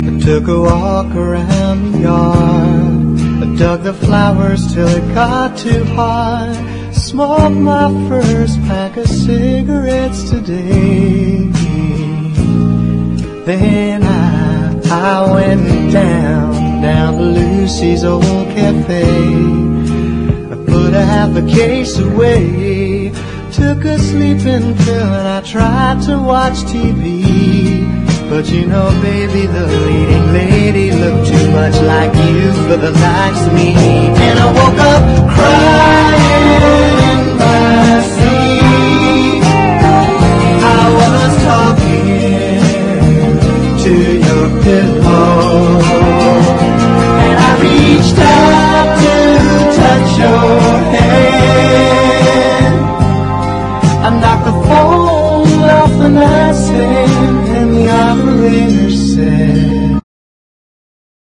NEO-ACO/GUITAR POP / INDIE POP